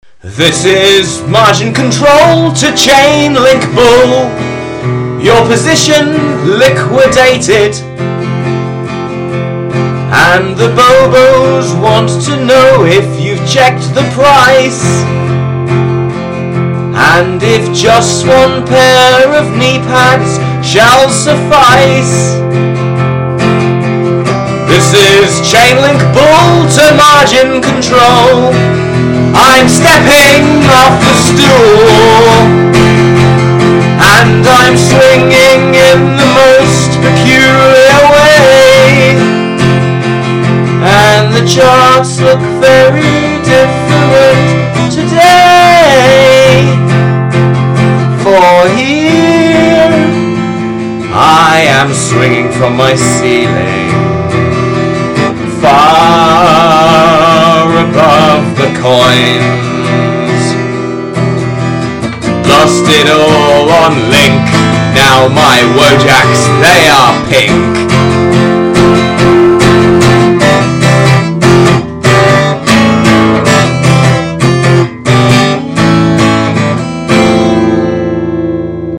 music Sung and written by anonymous